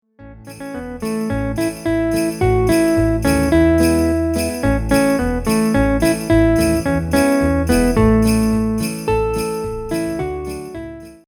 You can hear the bells clearly in the backing track: